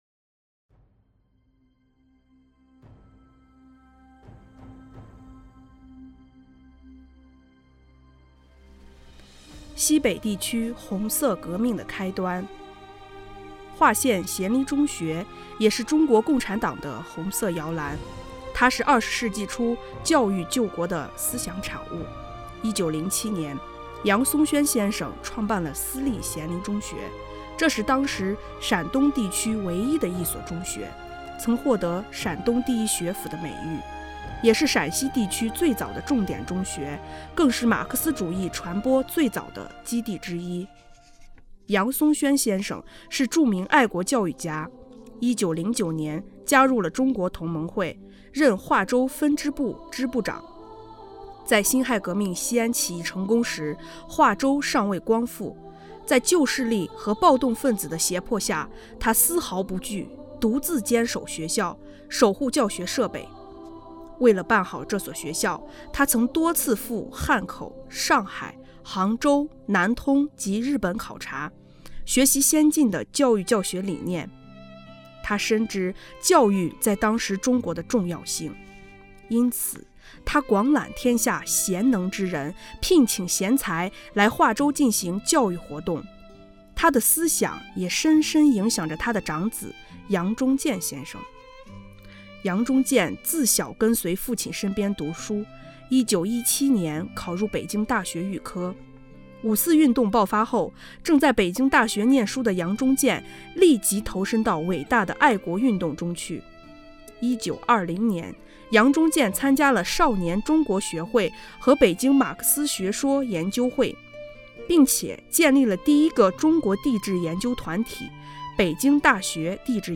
【红色档案诵读展播】西北地区红色革命的开端